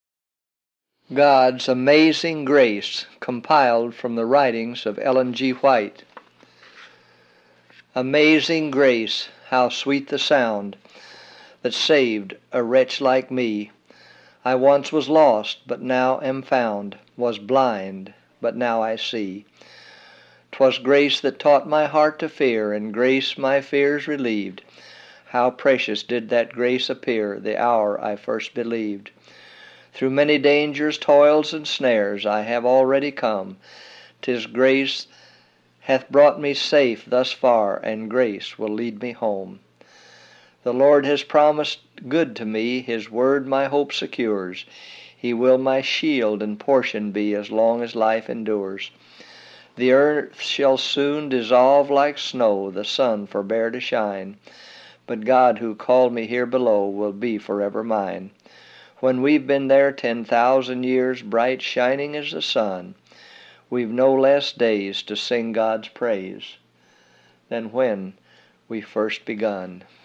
God’s Amazing Grace audio book